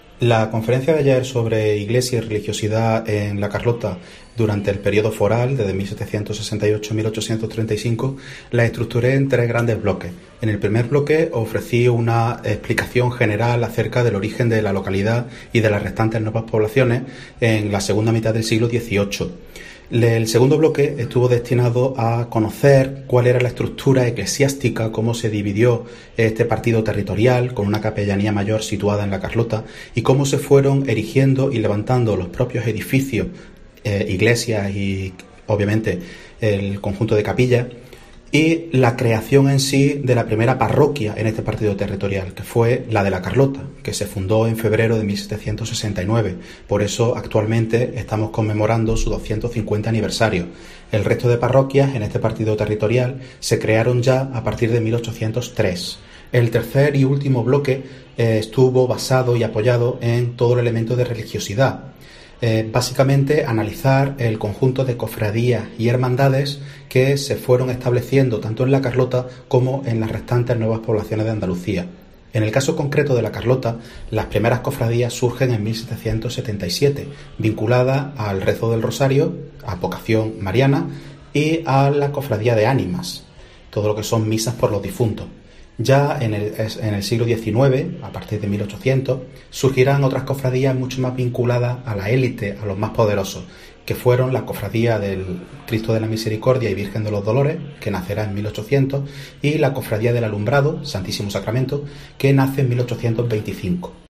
Se trata de la primera conferencia con motivo del 250 aniversario de la fundación de la parroquia de la Inmaculada Concepción en La Carlota